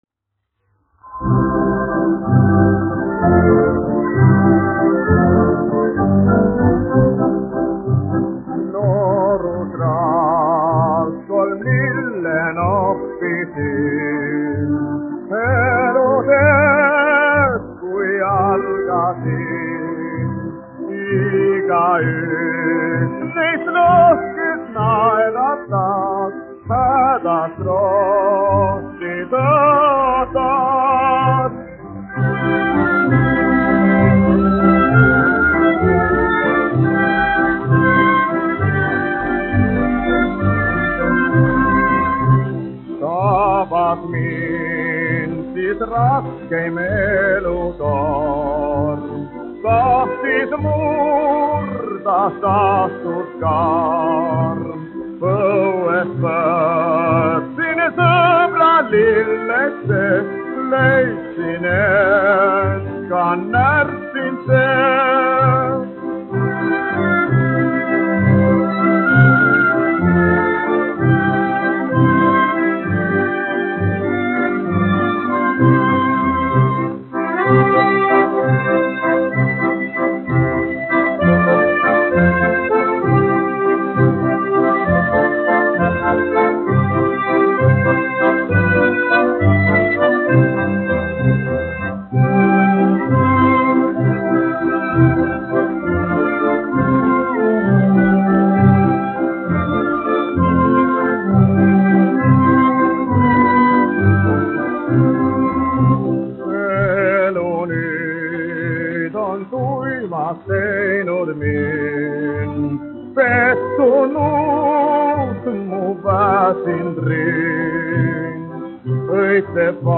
dziedātājs
1 skpl. : analogs, 78 apgr/min, mono ; 25 cm
Populārā mūzika
Skaņuplate